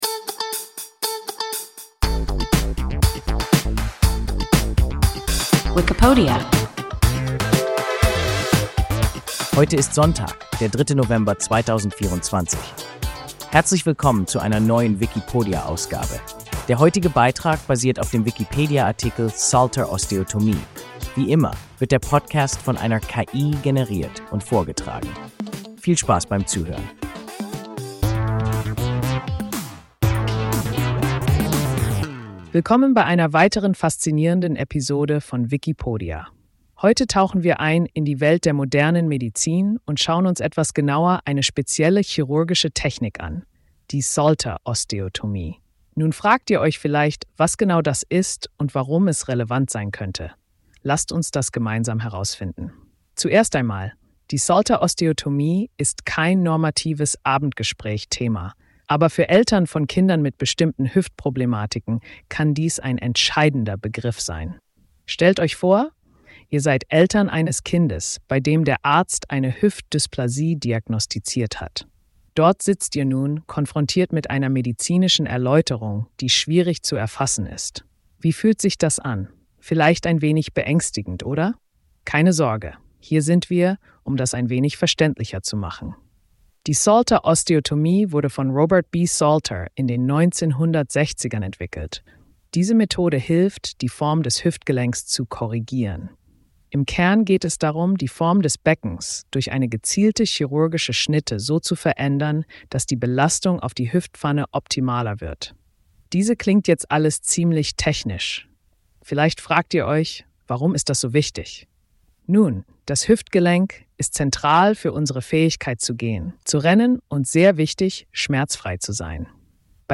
Salter-Osteotomie – WIKIPODIA – ein KI Podcast